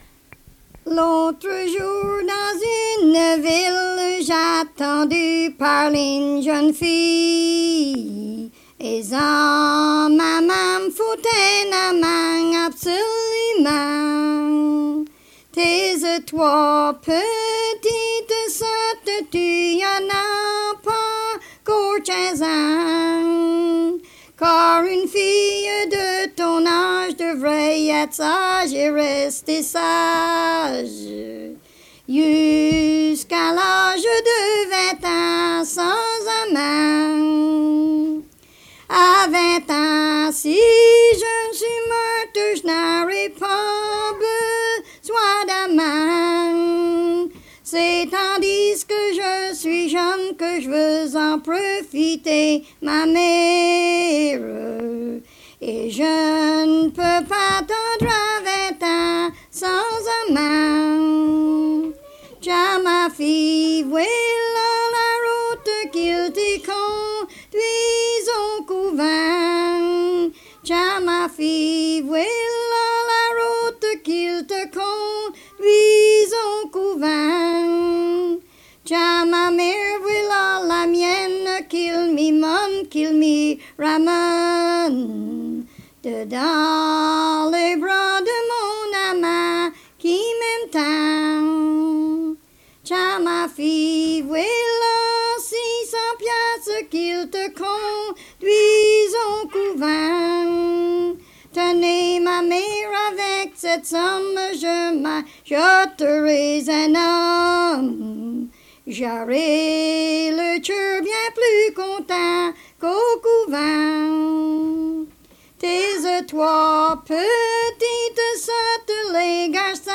Chanson
Emplacement Upper Ferry